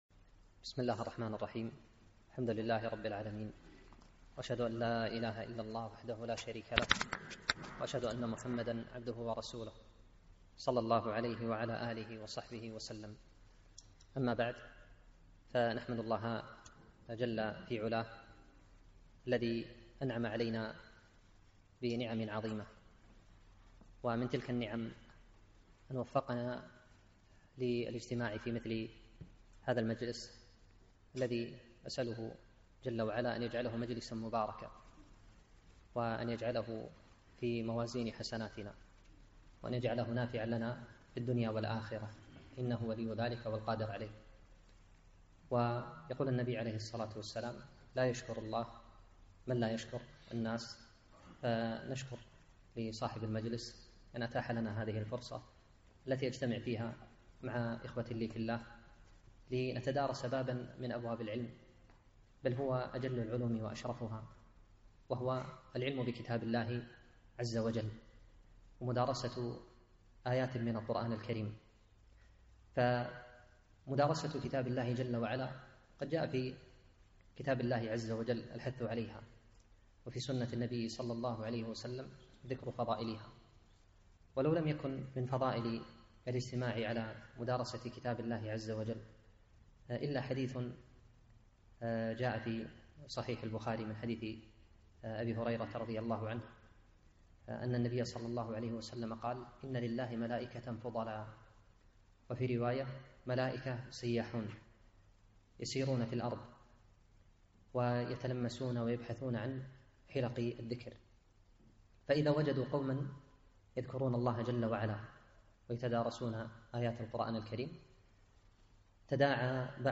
محاضرة - تأملات في سورة الفاتحة